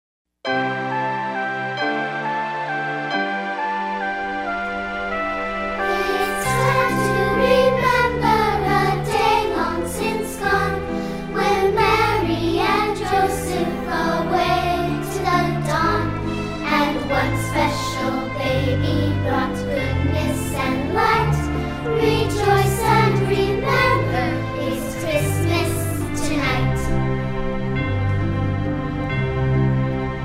▪ The full-length music track with vocals.